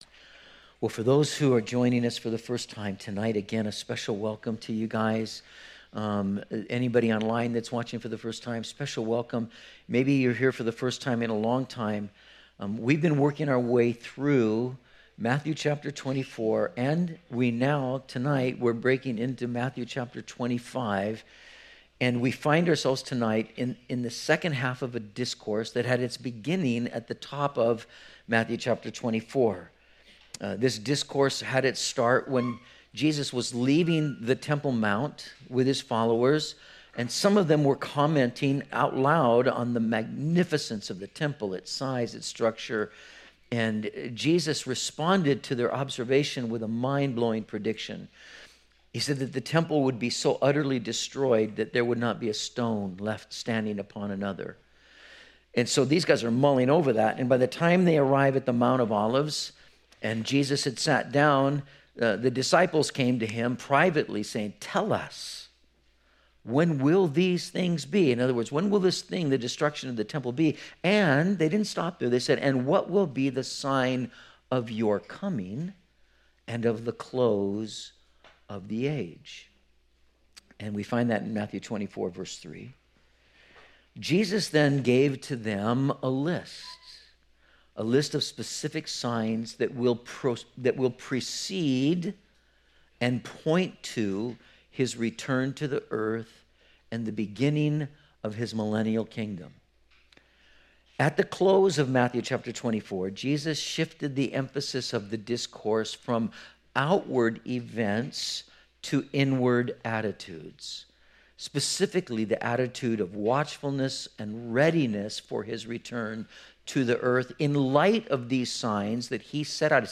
04/15/19 When the Kingdom Comes - Metro Calvary Sermons